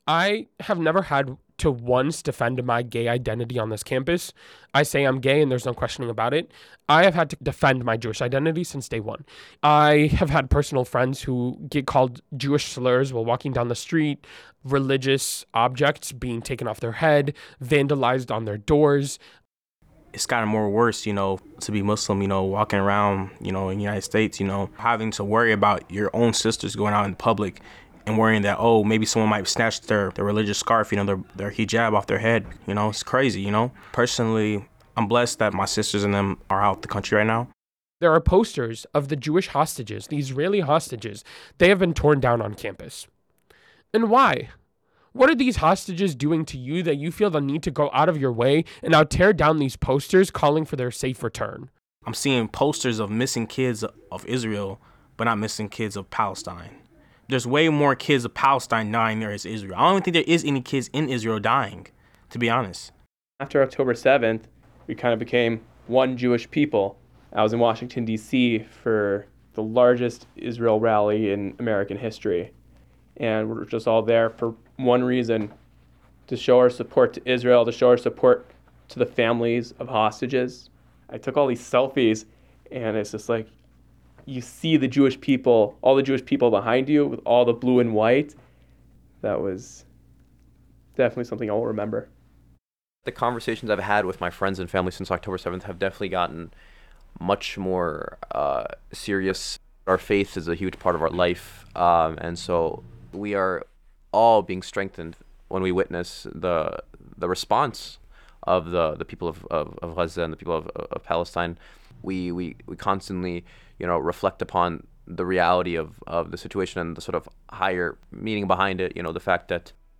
So with tensions rising on campus, University of Illinois student journalists spoke with members of our community most impacted by the crisis.  During interviews at the Chabad Center for Jewish Life, Illini Hillel, the Central Illinois Mosque and the University YMCA, speakers were granted anonymity to express themselves freely.